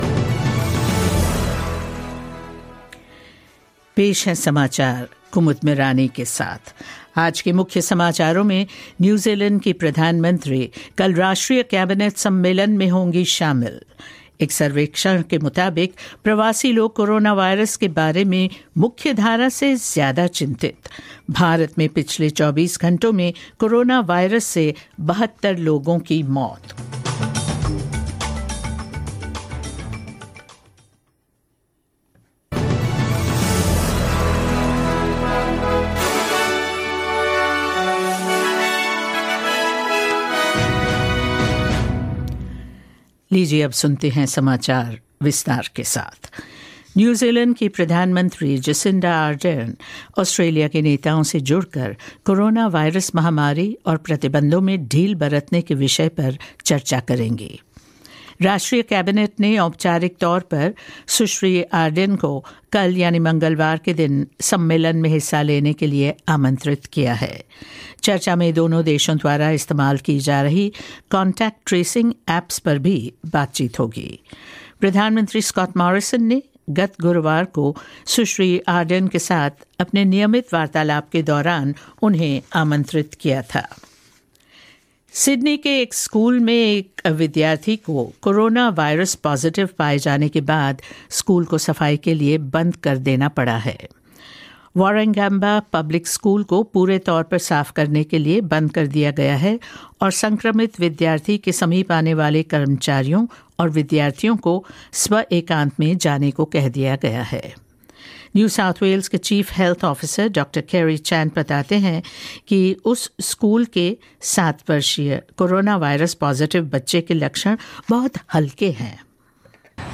News in Hindi 04 May 2020